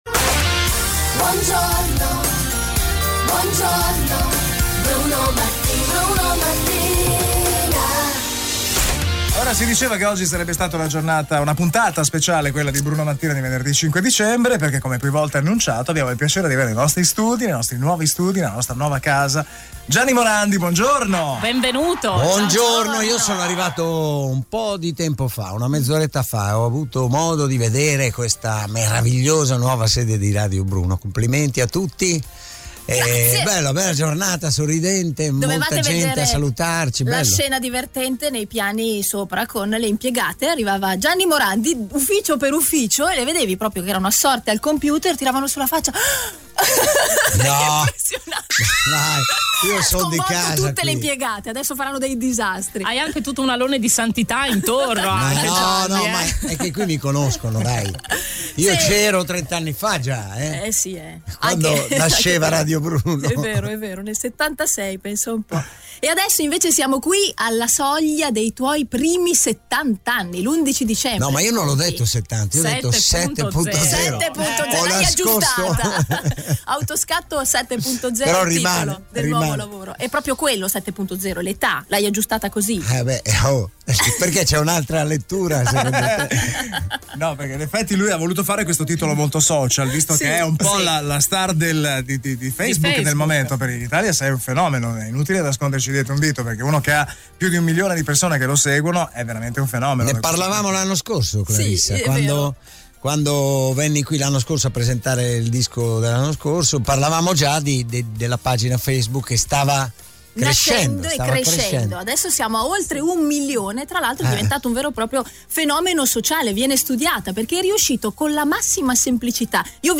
Gianni Morandi è stato in diretta dai nostri studi per parlarci di questo progetto e molto altro.
Gianni-Morandi-Intervista-0512.MP3